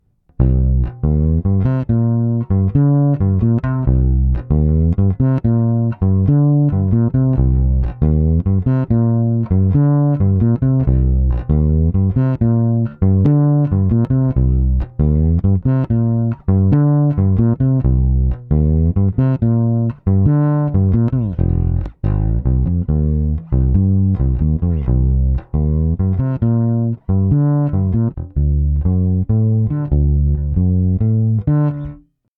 Klingt nach 70's Funk.